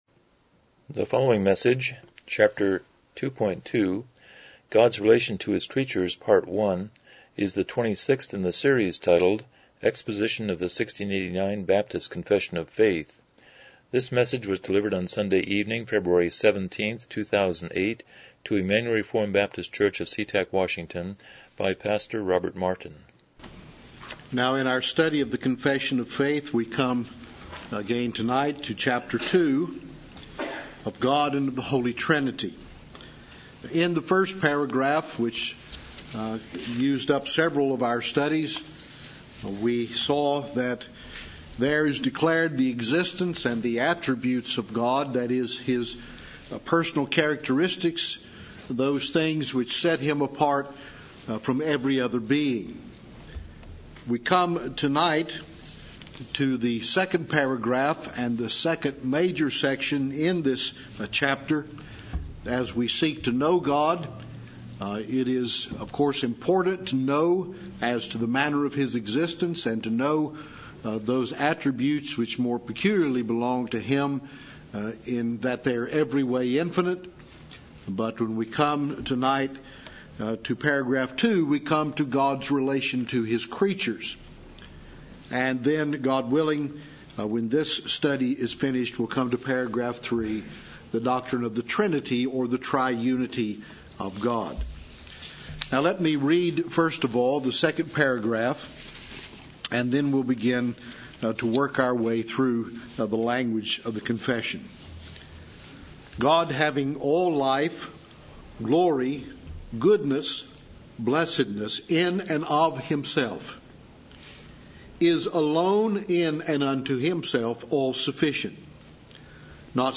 Series: 1689 Confession of Faith Service Type: Evening Worship